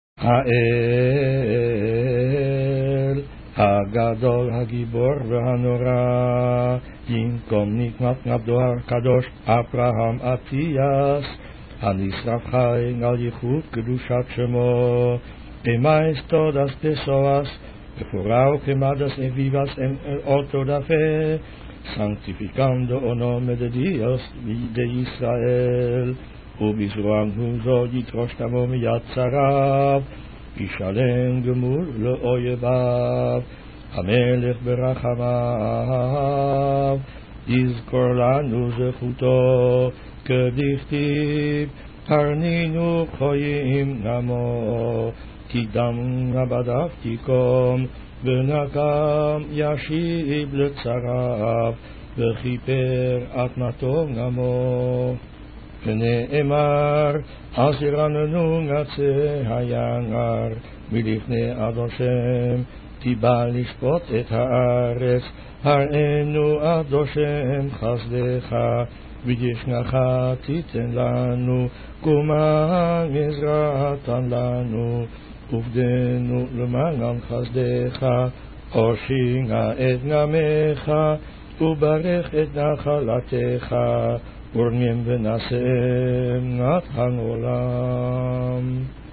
On particular occasions, a special Hashcaba (memorial-prayer) is recited in the memory of our ancestors who died at the Auto Da fé (burning at the stake) at the hands of the Spanish and Portuguese Inquisitions.